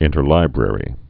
(ĭntər-lībrĕrē)